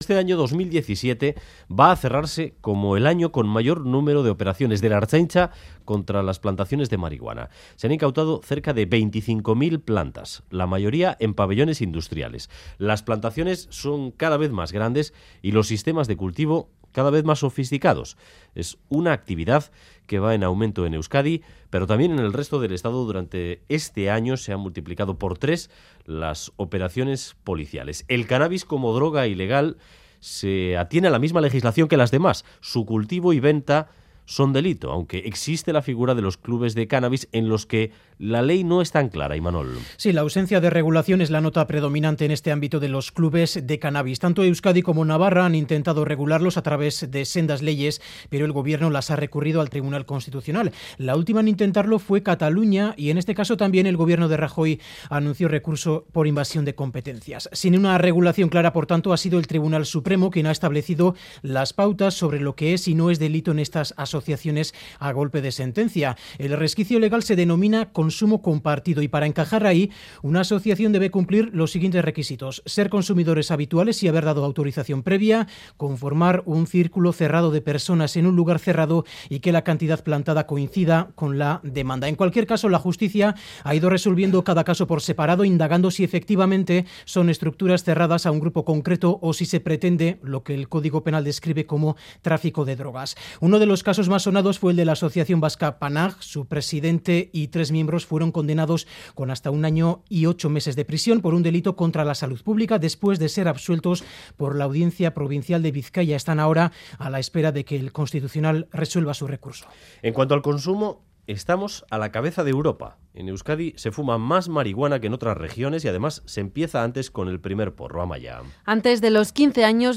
Entrevista sobre el aumento de las plantaciones y el consumo de marihuana en Euskadi.